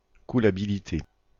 Ääntäminen
Ääntäminen (France), Bordeaux: IPA: /ɛ̃.kal.ky.la.bi.li.te/ Haettu sana löytyi näillä lähdekielillä: ranska Käännös Substantiivit 1. incalculabilidad Määritelmät Substantiivit Caractéristique de ce qui est incalculable .